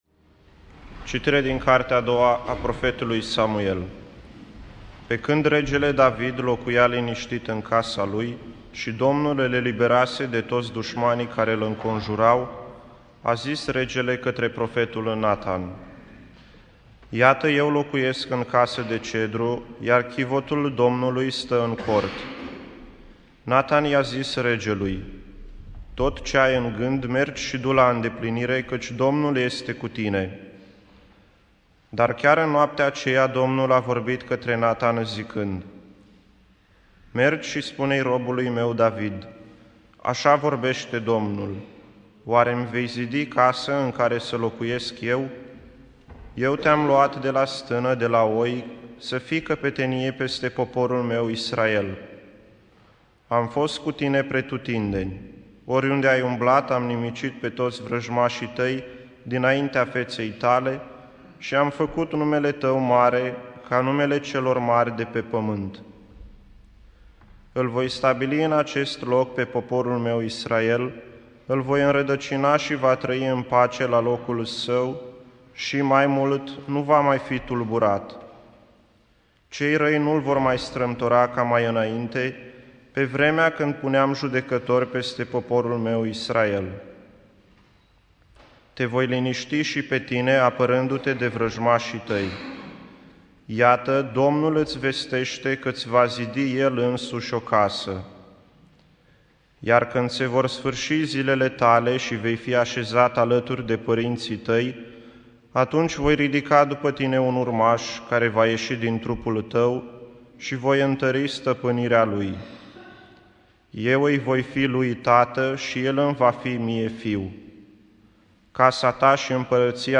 Biblioteca - Predici la Radio Iasi